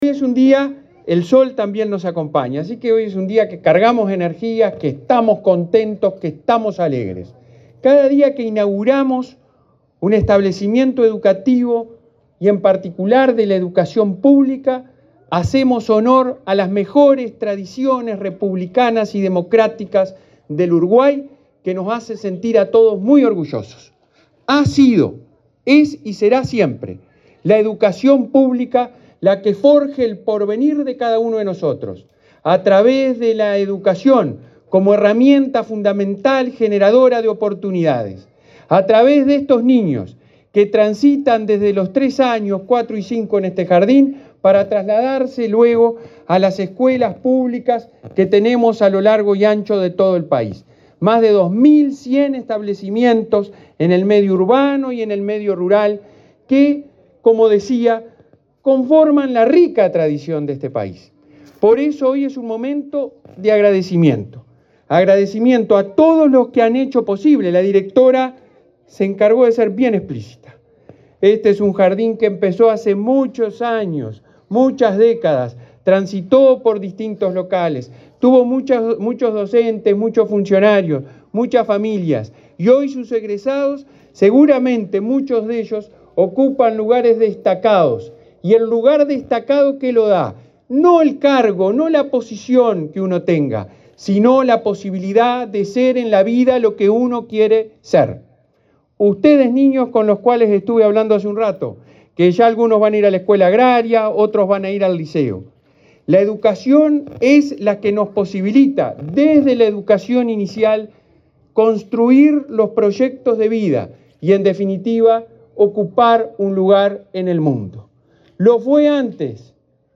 Palabras del presidente de ANEP, Robert Silva
El presidente de la Administración Nacional de Educación Pública (ANEP), Robert Silva, encabezó en Rocha la inauguración del jardín n.° 98, de jornada